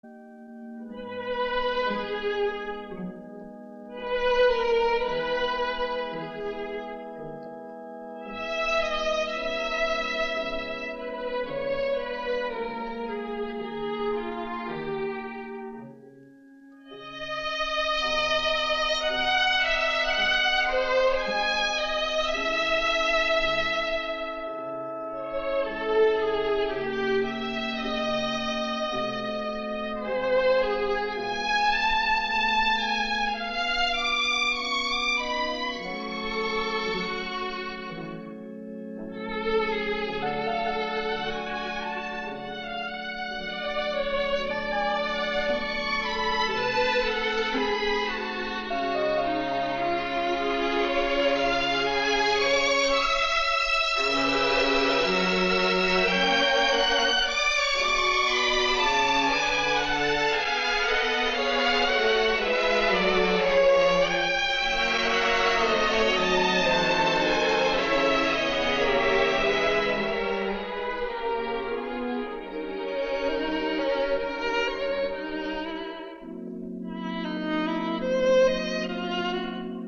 triste y conmovedora música incidental